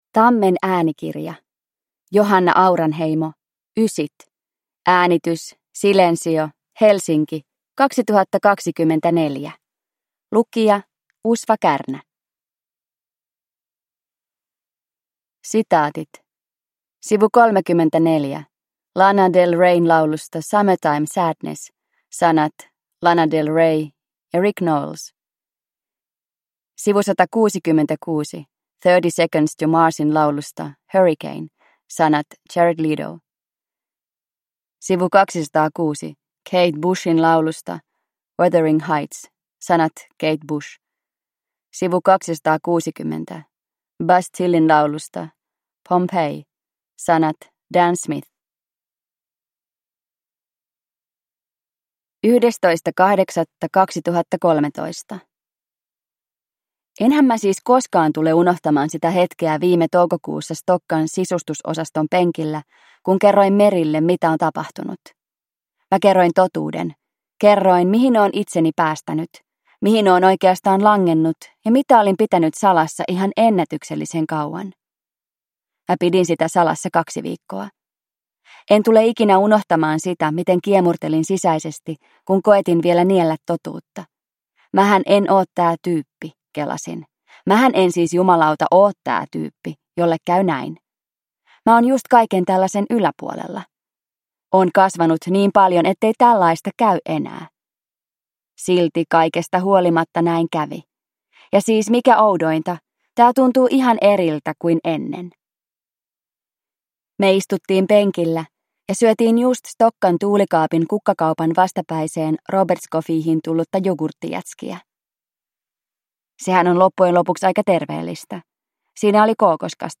Ysit – Ljudbok